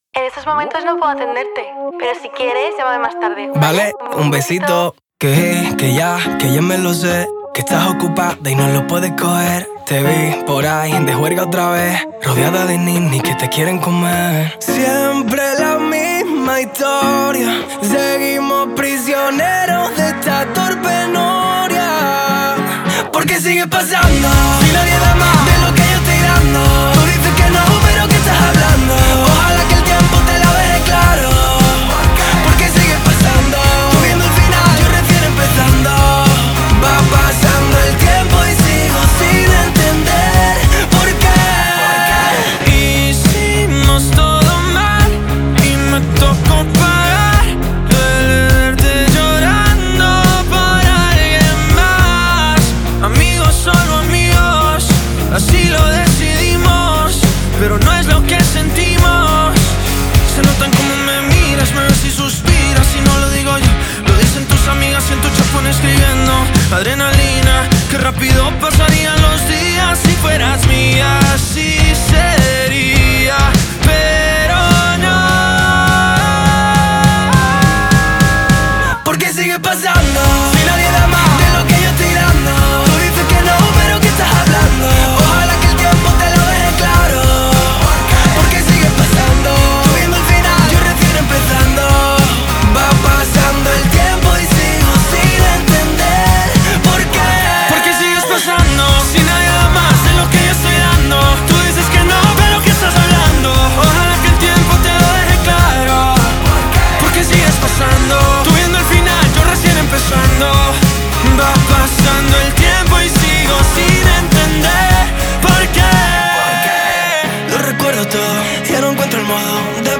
Una colaboración que rebosa rabia y